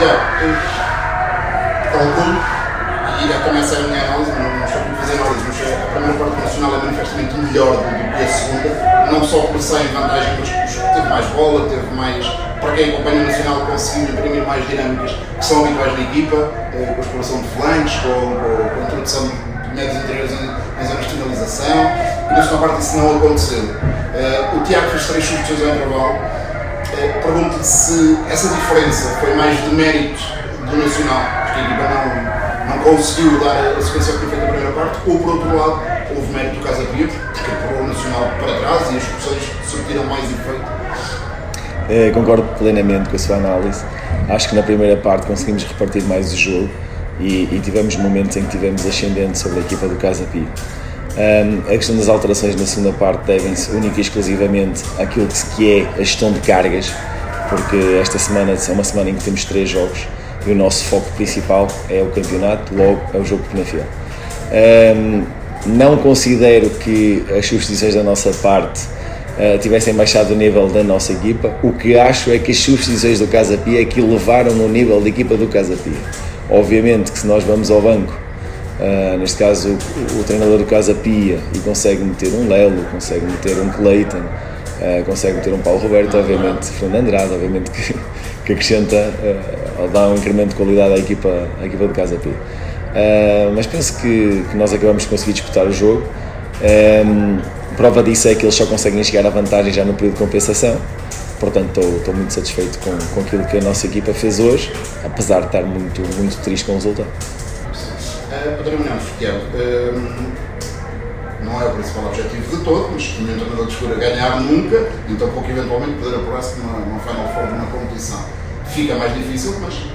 Na conferência de imprensa realizada no final do encontro